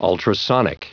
Prononciation du mot ultrasonic en anglais (fichier audio)
Prononciation du mot : ultrasonic